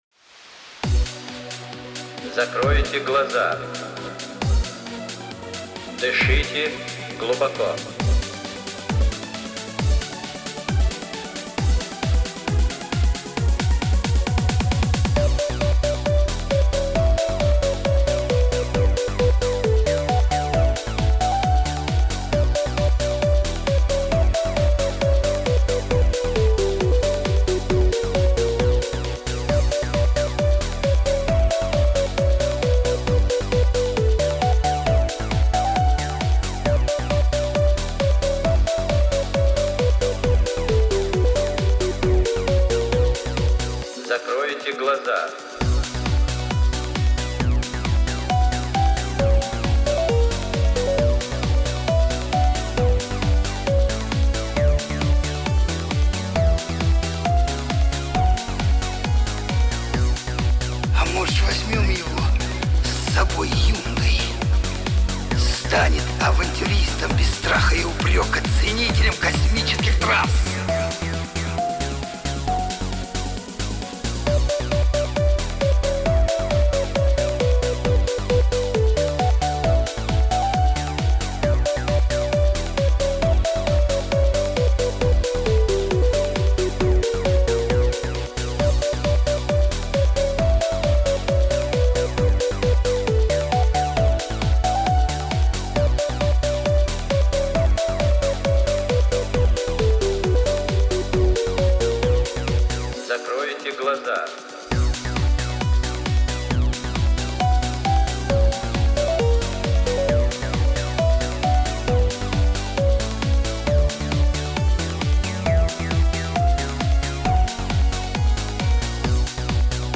минусовка версия 16154